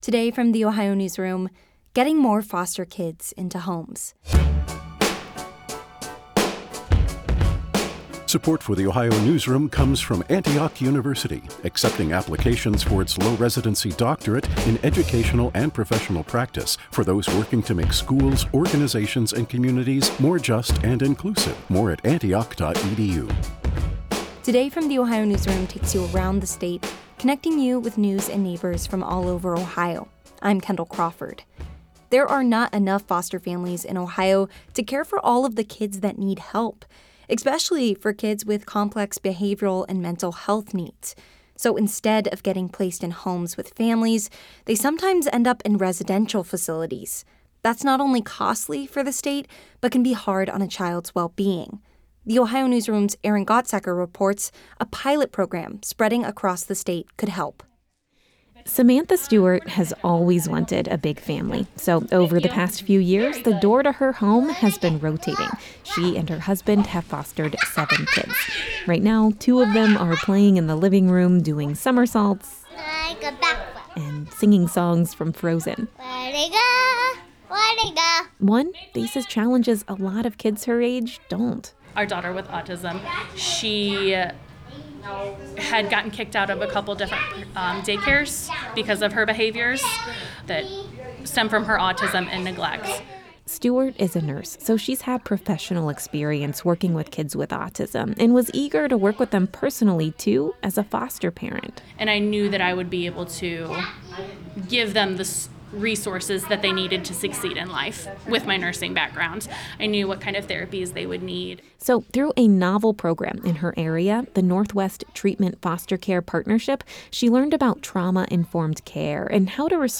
On a summer afternoon, two of those children play in the living room, doing somersaults and singing songs from “Frozen."